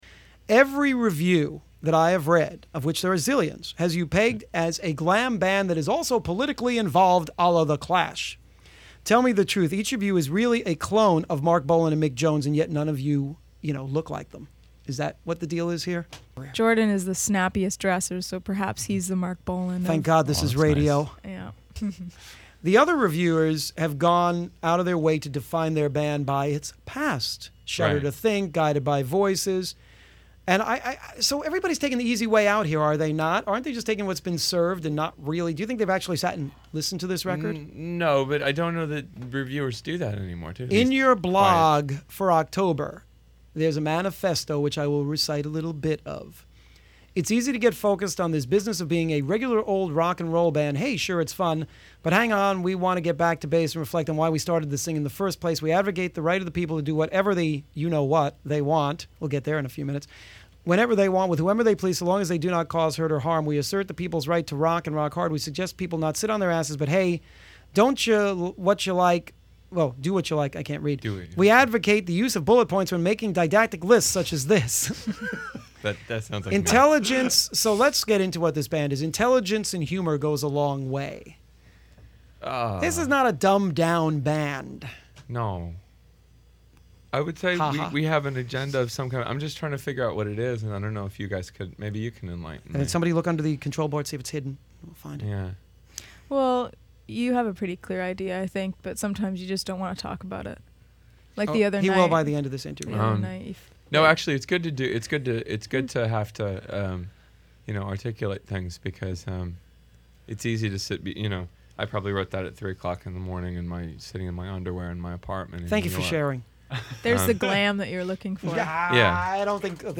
What it does make them was worthy of an interview on JAMS which not only "...Got The Joy."